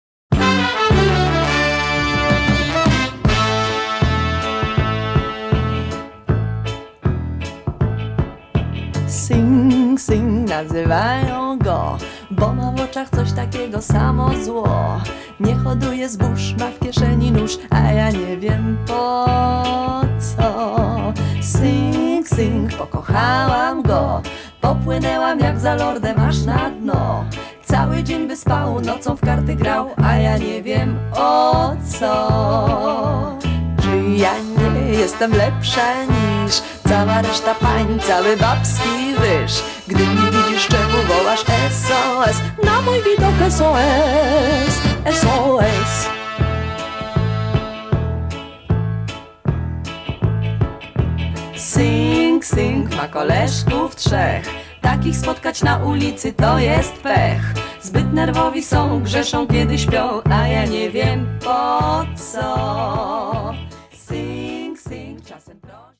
BAND DUET TEXT
VOC GUITAR KEYB BASS DRUMS   TEKST